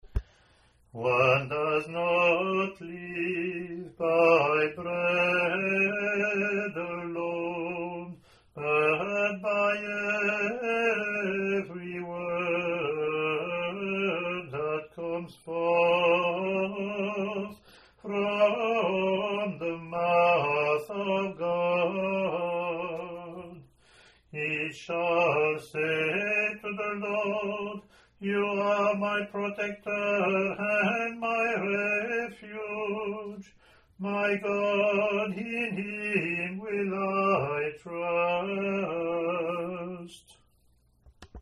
English antiphon – English verse
lt01-comm-eng-pw.mp3